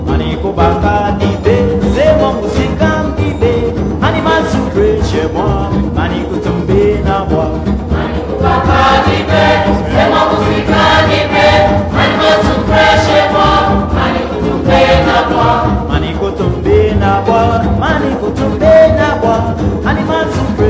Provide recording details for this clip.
l audio cassette